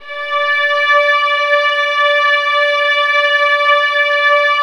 VIOLINS EN5.wav